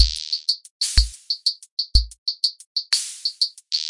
硬核嘻哈鼓 " loop05
描述：带打击乐的低音鼓循环。由几个样本组成。这是我自己的作品。
Tag: 桶循环 嘻哈